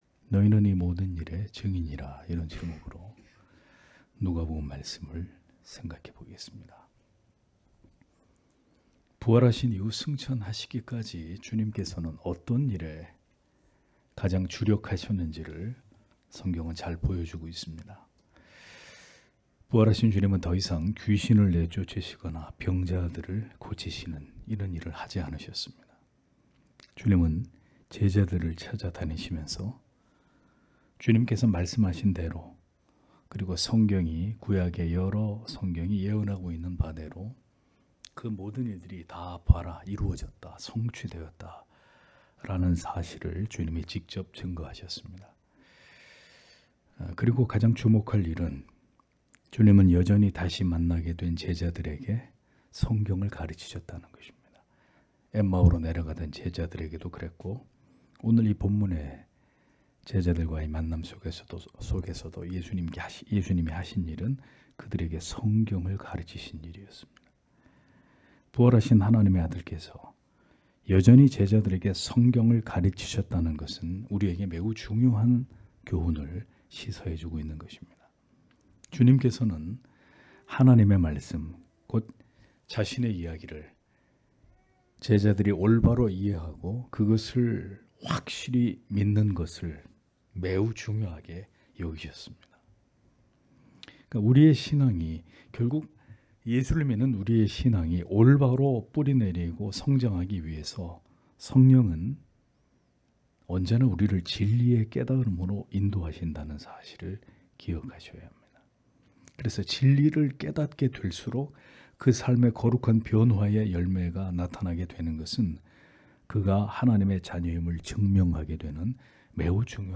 금요기도회